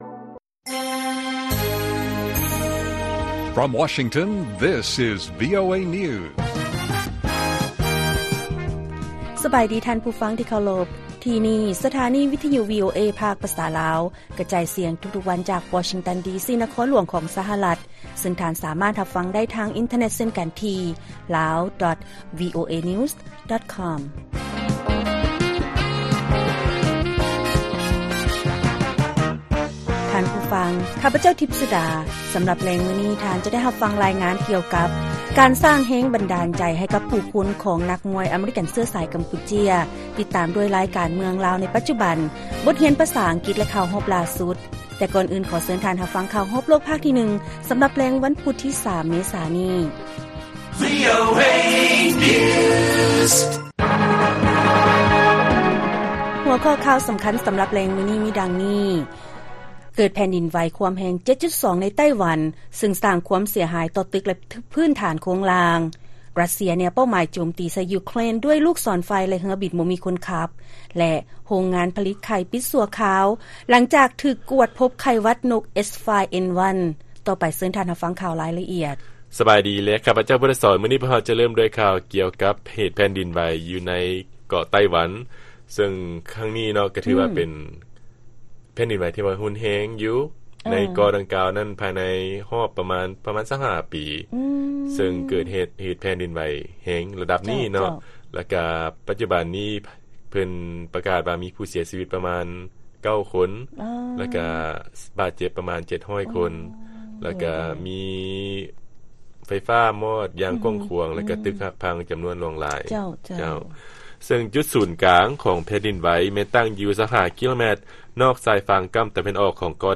ລາຍການກະຈາຍສຽງຂອງວີໂອເອ ລາວ: ແຊມຕີມວຍ ຫຼືຄິກບັອກຊິງ ຊາວກຳປູເຈຍ ສົ່ງເສີມກິລາຕິມວຍກຳປູເຈຍ ໃນສະຫະລັດ